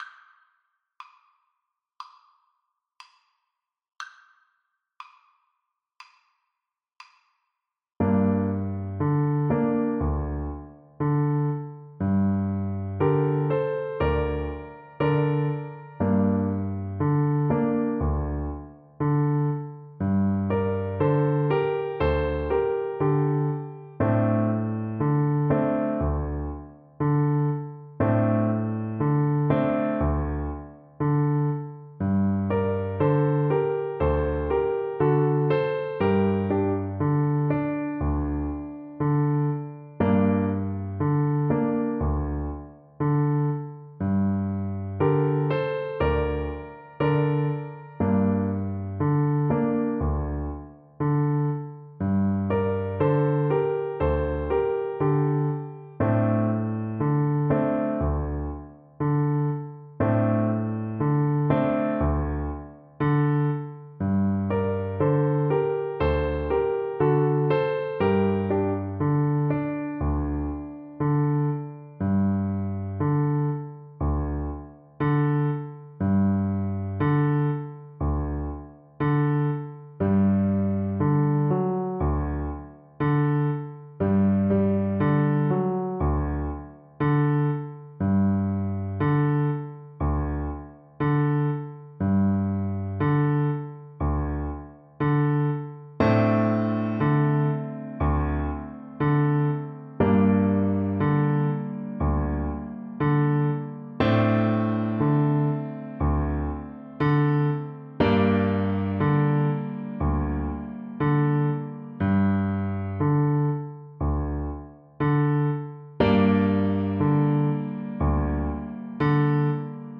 Animato